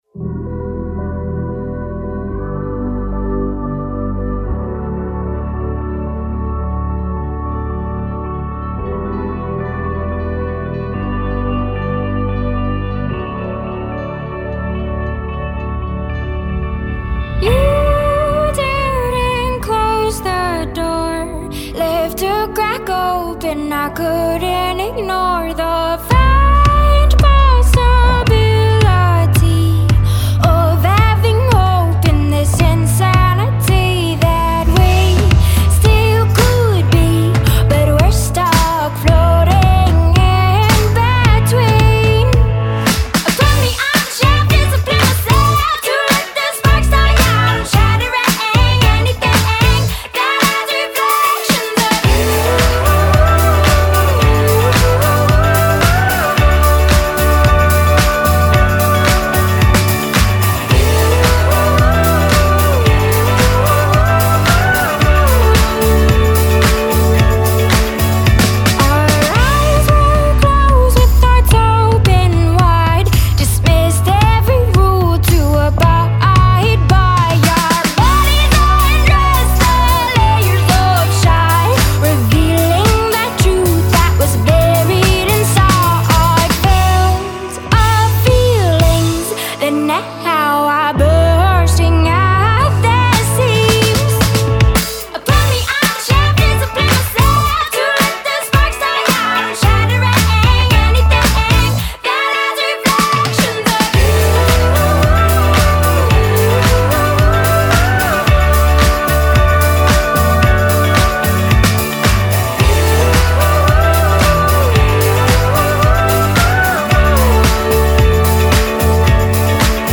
really harkens back to the 70’s disco vibe